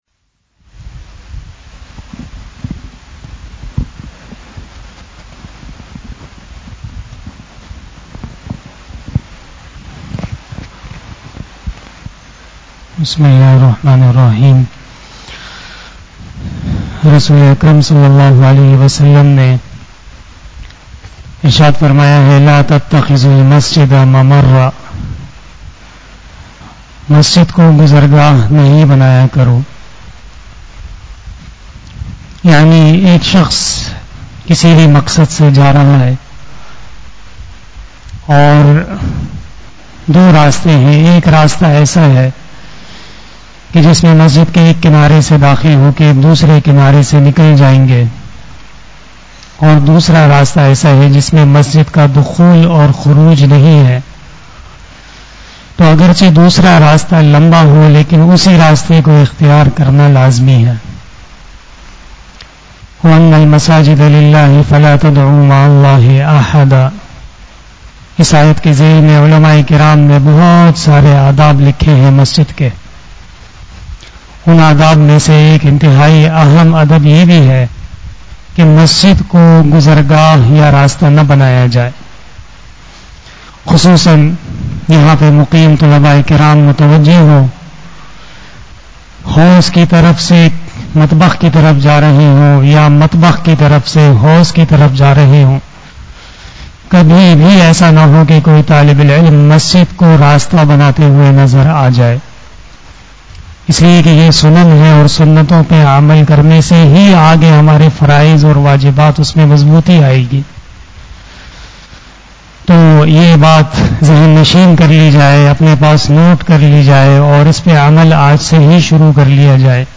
After Asar Namaz Bayan